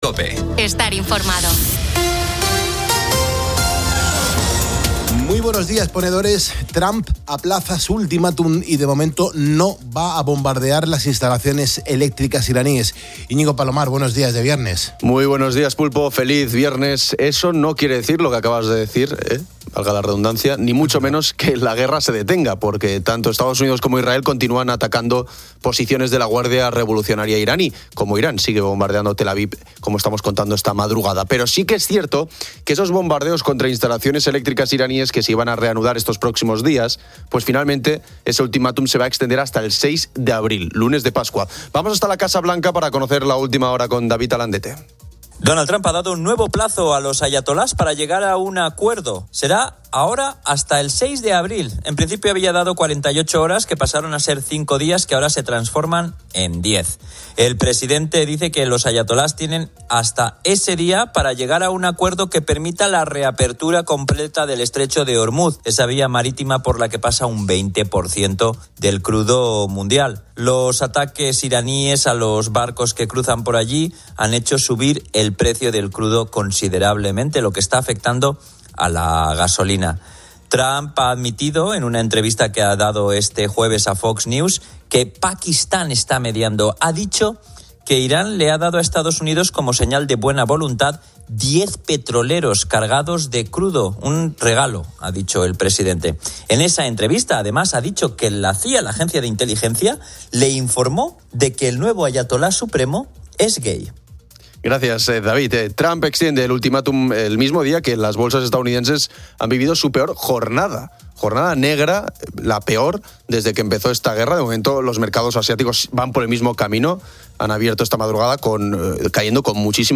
Asimismo, se entrevista al cantante Antoñito Molina, quien presenta su nuevo disco "Imparable" y comparte su alegría por la paternidad, el éxito de su gira y la Gaviota de Plata en Viña del Mar.